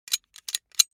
دانلود آهنگ کلیک 27 از افکت صوتی اشیاء
دانلود صدای کلیک 27 از ساعد نیوز با لینک مستقیم و کیفیت بالا
جلوه های صوتی